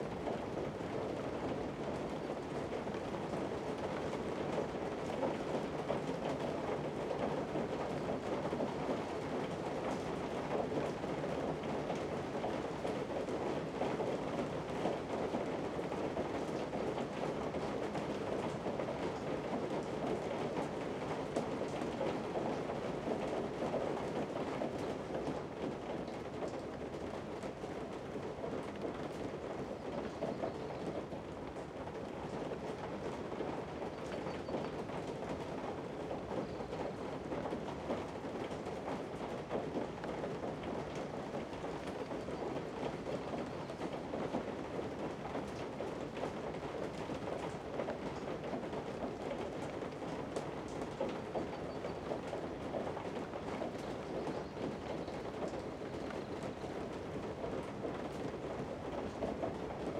BGS Loops / Interior Day
Inside Day Rain.wav